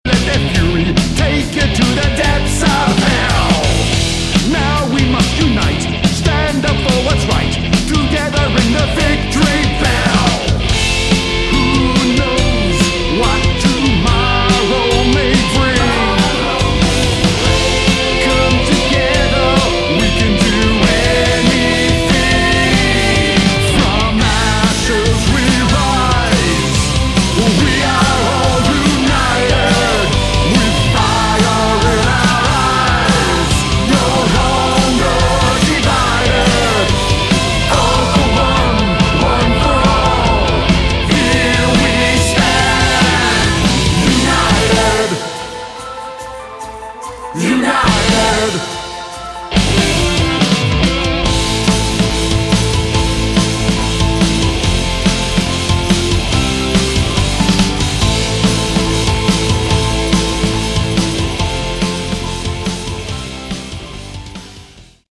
Category: Melodic Metal
vocals, keyboards, guitars
rhythm and lead guitars
backing vocals